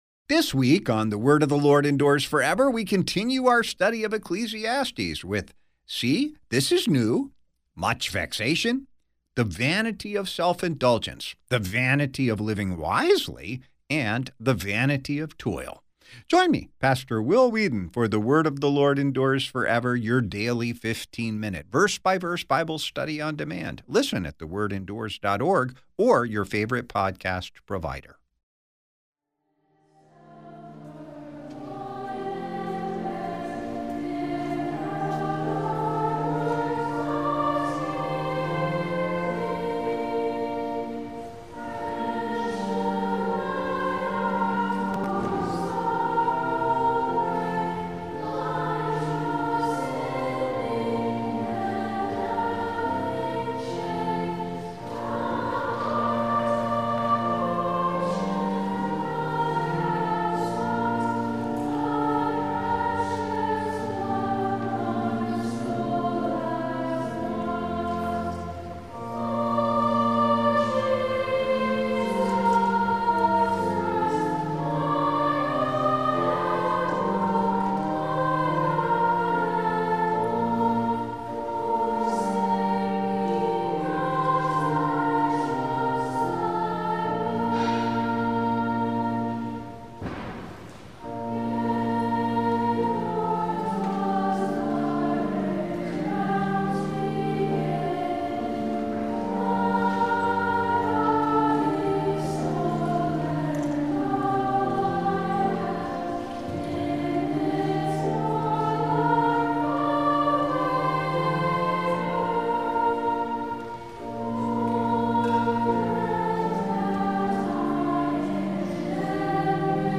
On the campus of Concordia Theological Seminary, Fort Wayne, Indiana.